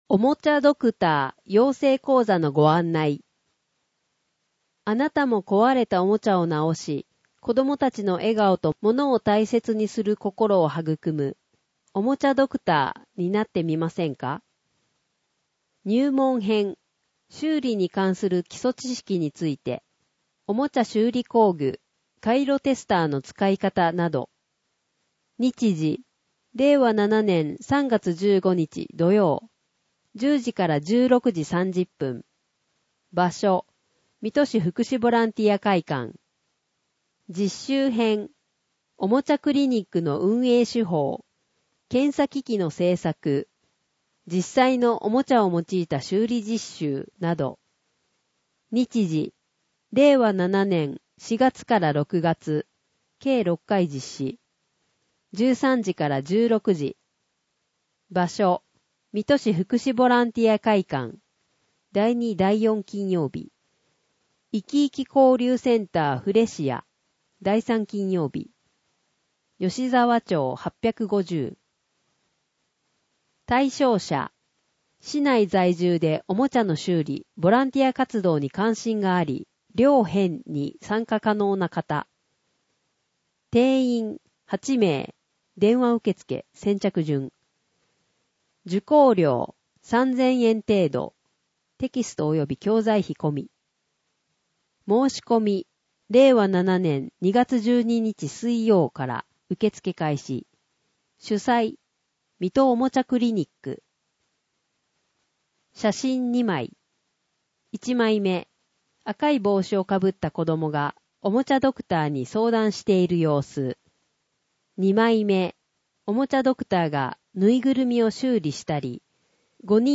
音声ガイド
（音声データ作成：音訳ボランティア「こだま」）